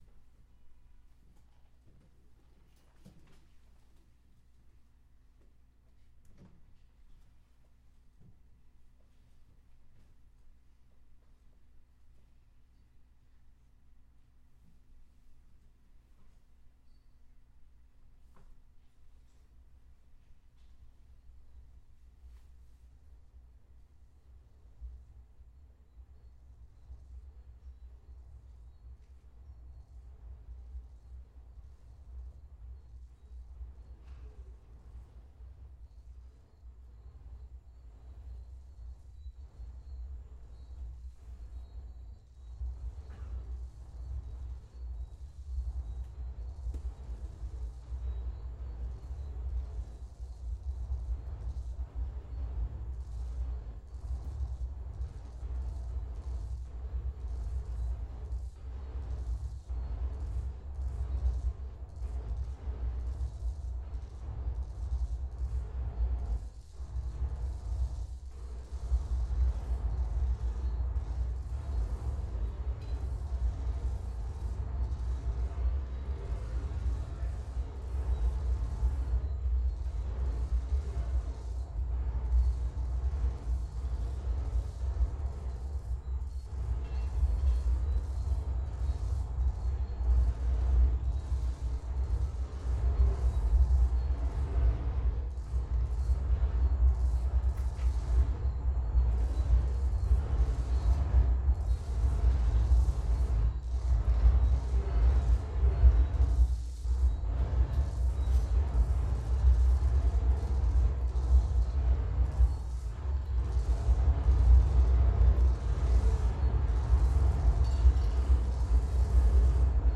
17-string electric bass
contrabass
and drums